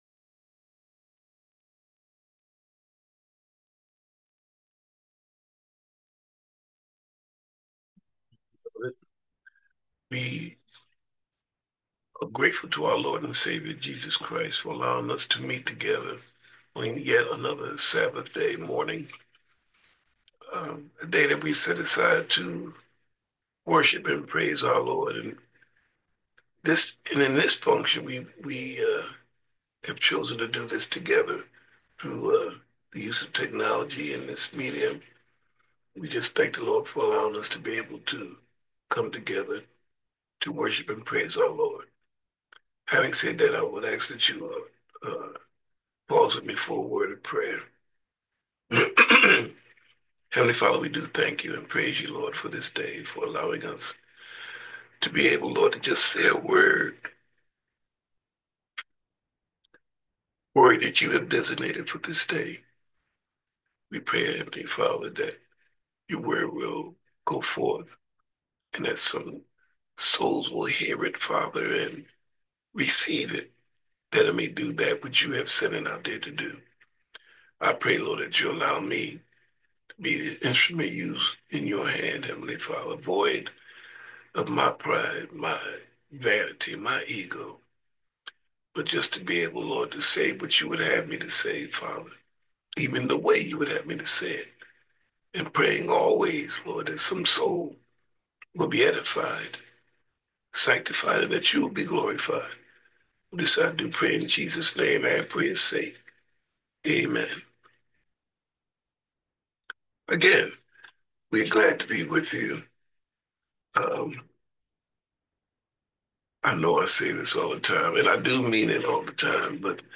Are You Serving Your Purpose? Sermon - St James Missionary Baptist Church